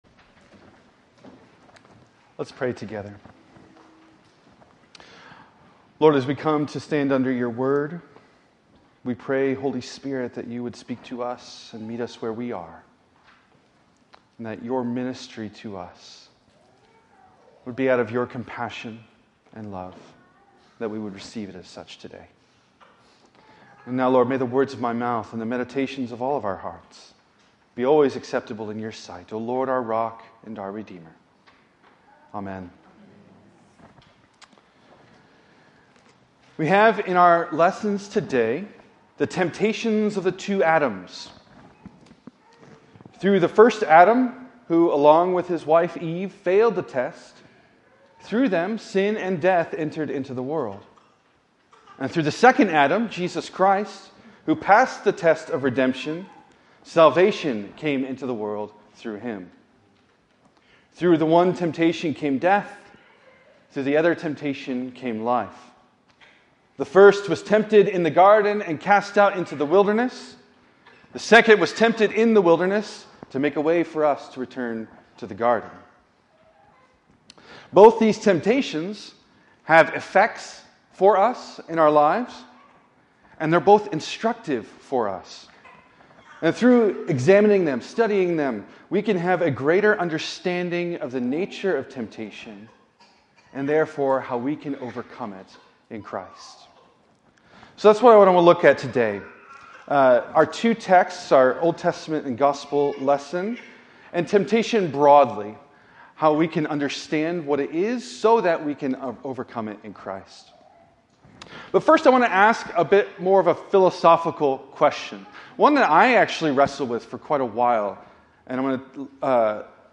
preaches on the nature of temptation, how it is an opportunity for us to grow in love for God, and how we might learn to overcome it with Christ.